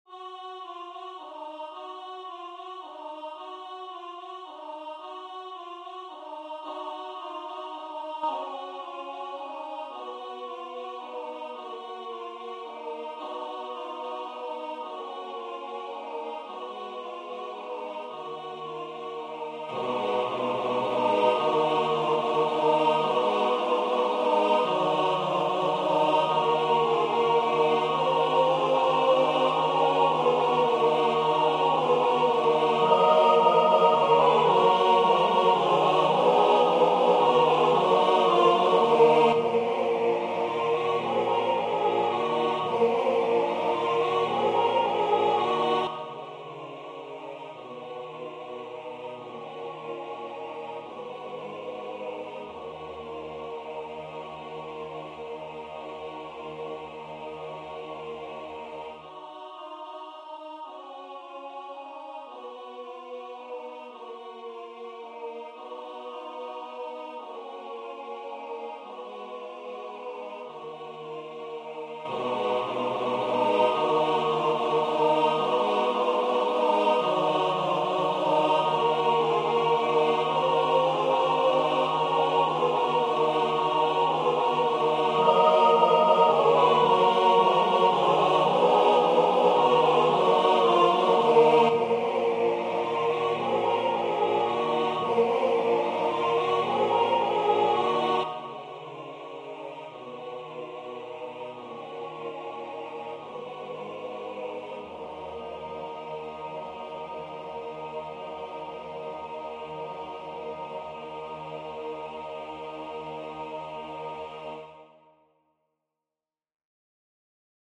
MP3 version voix synth.